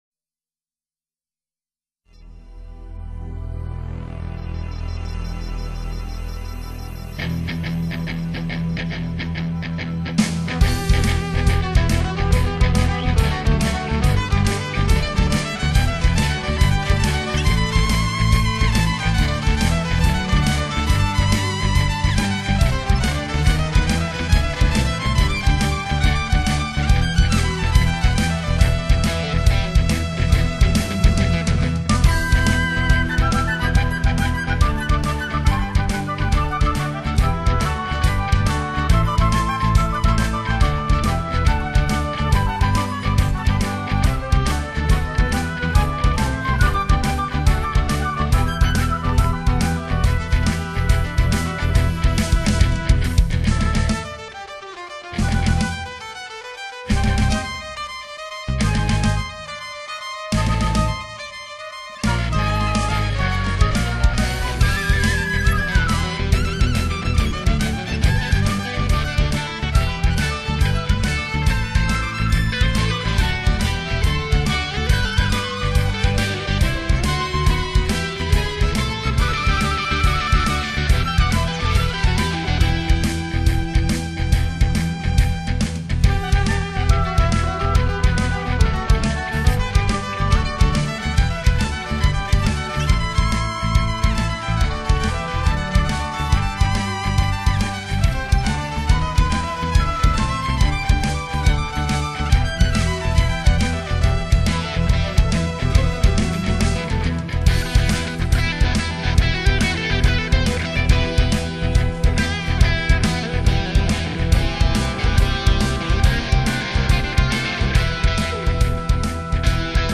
八个受欧美知名音乐院完整训练出身的年轻古典乐手
以非常狂热的电子风格加上世界音乐的素材写成，听来节奏感，电子气味十足